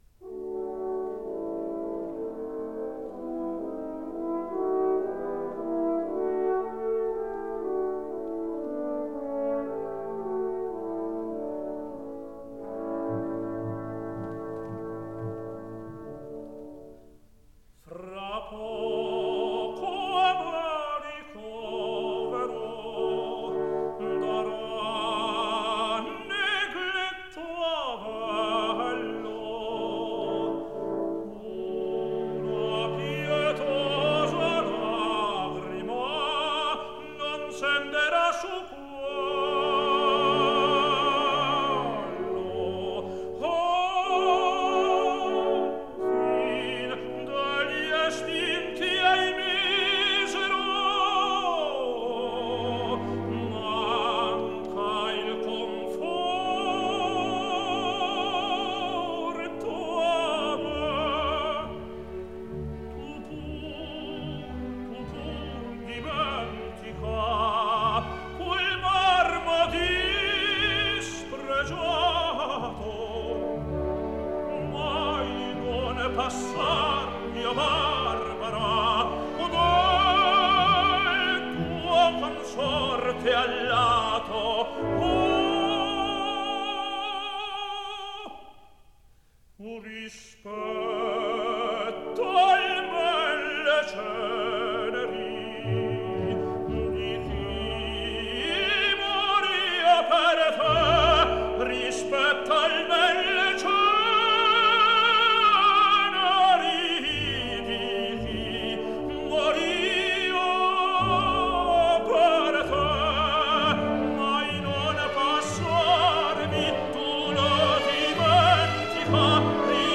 雷蒙德 男低音
诺尔曼雷文斯伍德的指挥官 男高音
第一幕，叙述悲剧的前提，庄严肃穆地奏起暗示悲剧气氛的前奏曲，然后开幕。